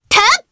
Selene-Vox_Attack1_jp.wav